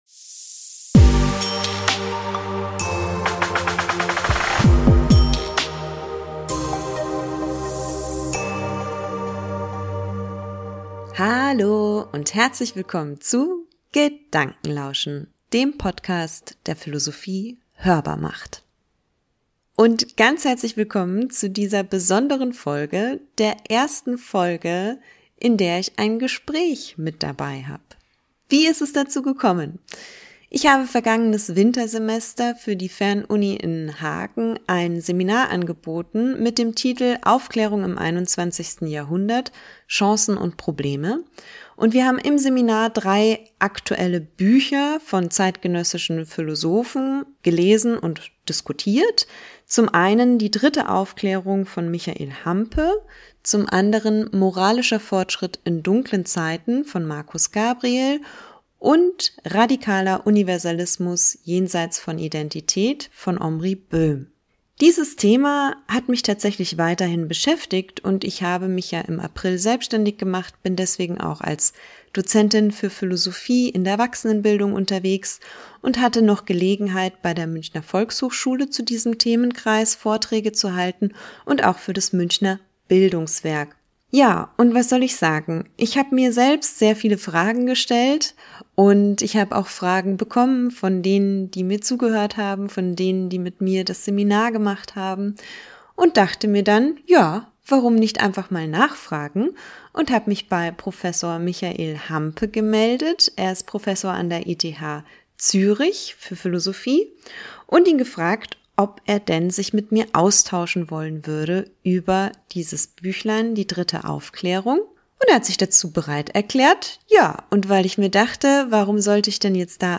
Heute habe ich einen Gast dabei: